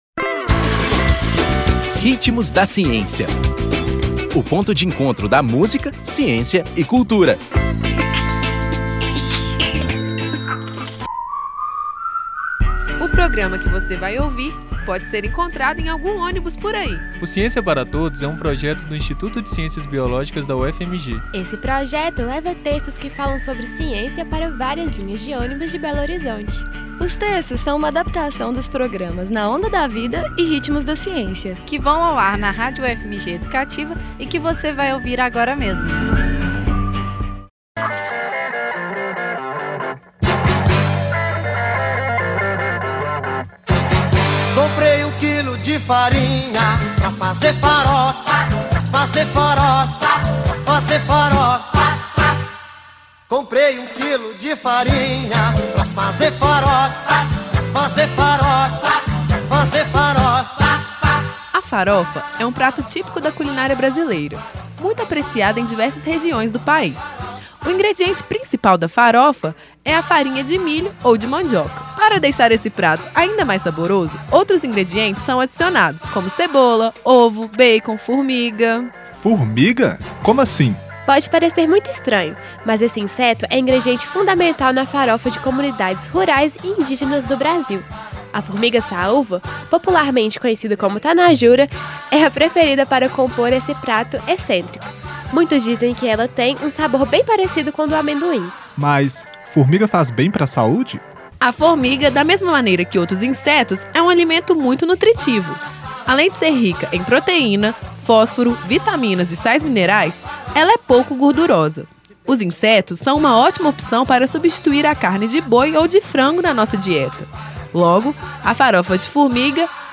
Nome da música: Farofa-fá
Compositor: Silvio Brito
Intérprete: Silvio Brito